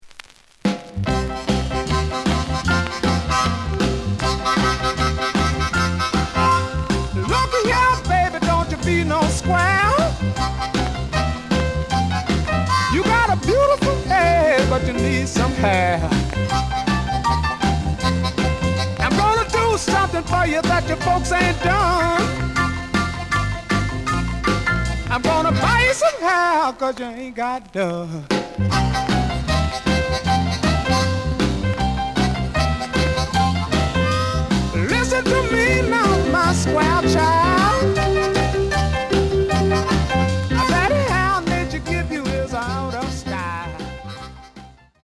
●Format: 7 inch
●Genre: Blues